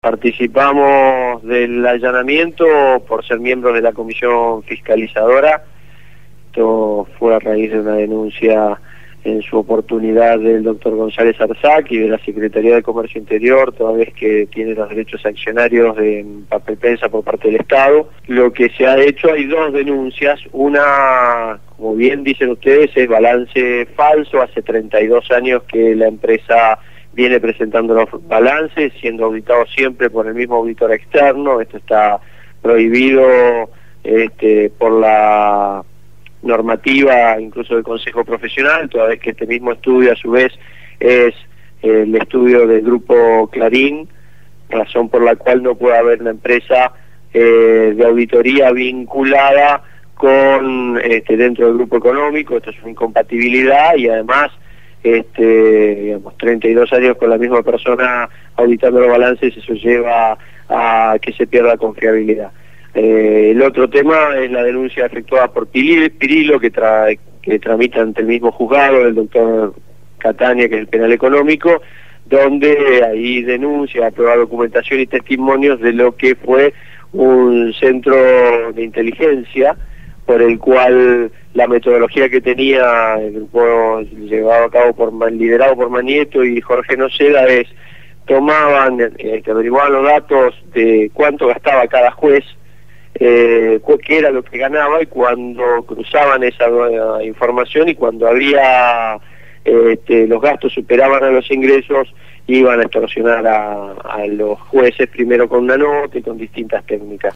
Daniel Reposo, titular de la SIGEN (Sindicatura General de la Nación) fue entrevistado